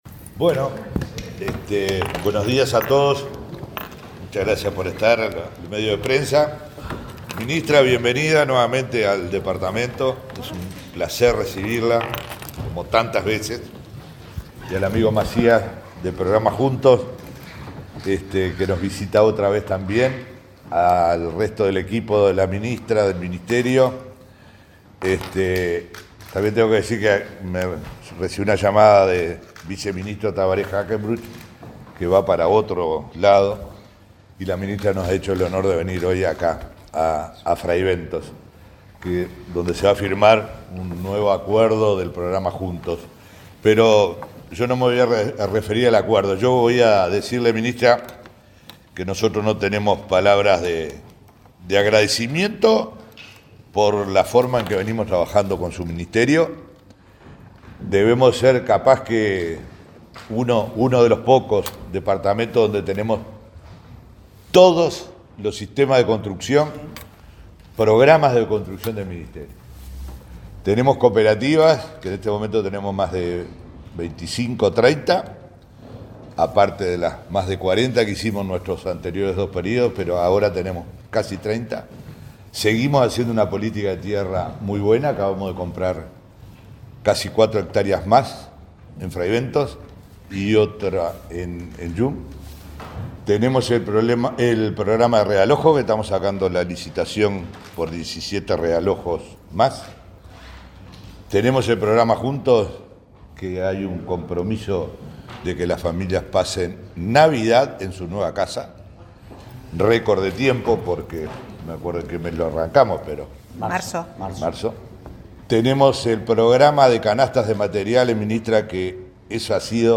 Palabras de autoridades en firma de convenio en Río Negro
Palabras de autoridades en firma de convenio en Río Negro 14/10/2022 Compartir Facebook X Copiar enlace WhatsApp LinkedIn El Ministerio de Vivienda, a través del programa Juntos, y la Intendencia de Río Negro firmaron este viernes 14 un acuerdo para el realojo de 30 familias de la ciudad de Fray Bentos. El intendente Omar Lafluf; el responsable de Juntos, Rody Macías, y la ministra Irene Moreira señalaron la importancia del convenio.